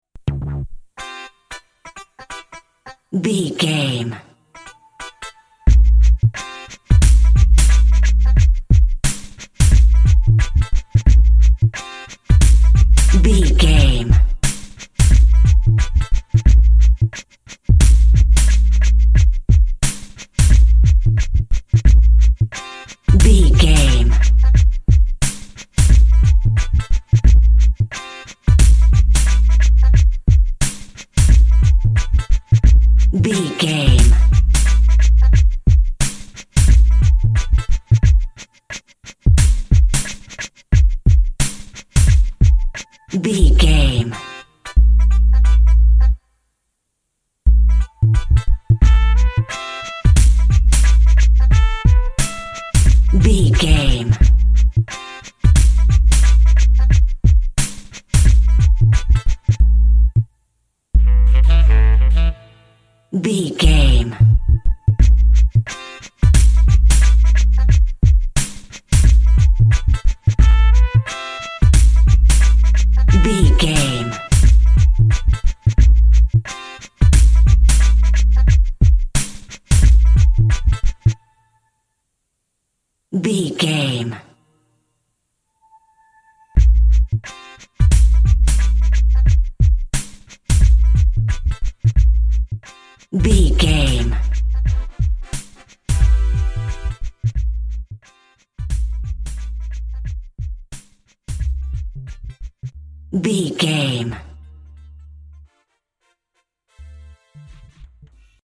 Aeolian/Minor
hip hop
turntables
synth lead
synth bass
hip hop synths
electronics